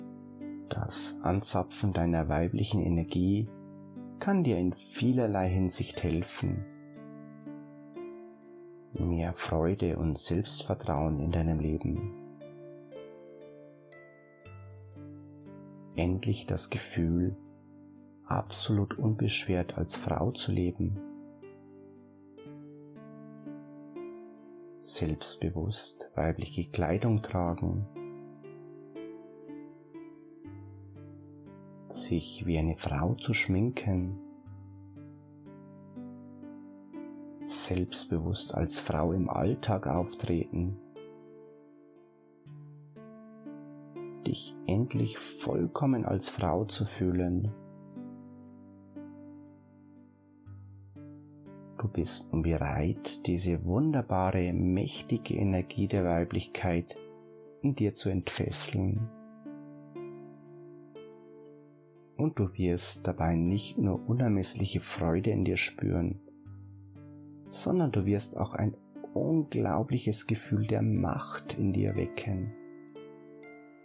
Nach einer herrlich tiefen Hypnoseeinleitung wird Ihnen und Ihrem Unterbewusstsein auf entspannende  Weise gezeigt, wie Sie mehr Weiblichkeit in Ihr Leben bringen und Ihre Männlichkeit vernachlässigen können.
Hörprobe: Q7002a – Entfessele Dein weibliches Ich – Hauptsitzung – Hörprobe